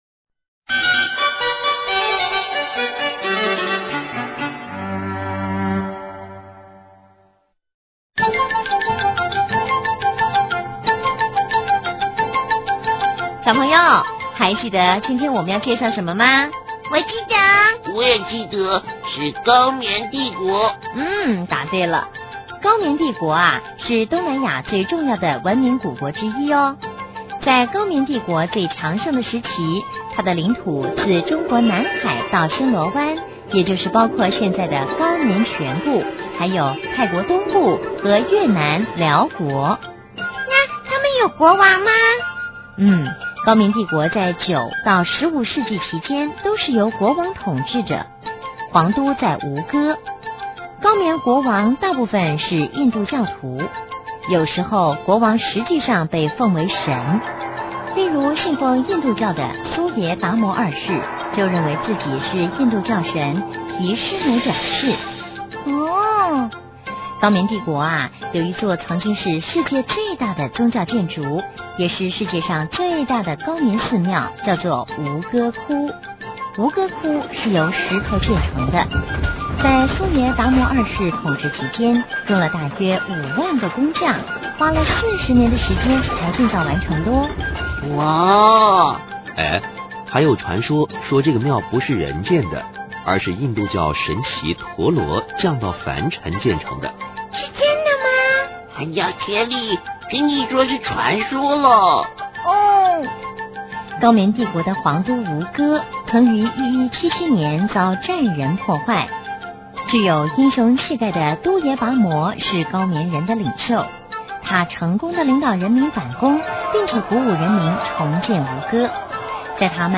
首页>mp3 > 儿童故事 > 高棉帝国